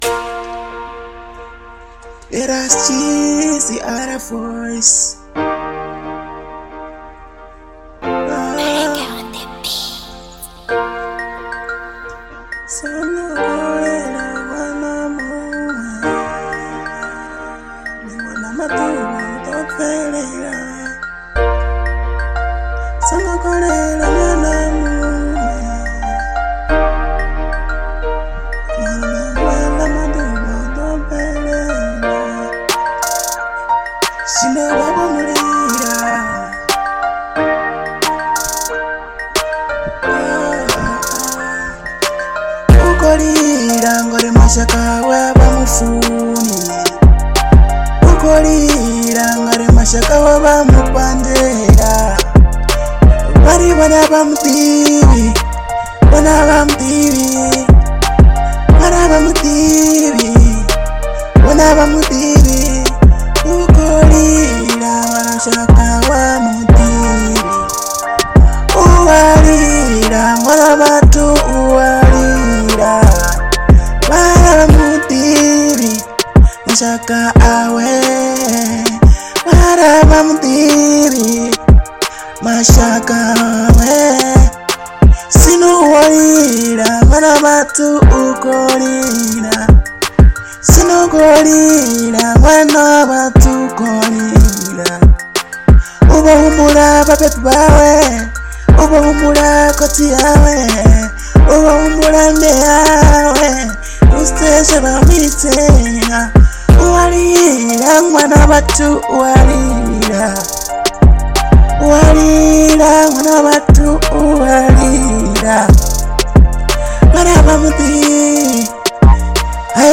3 months ago R & B